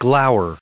added pronounciation and merriam webster audio
1573_glower.ogg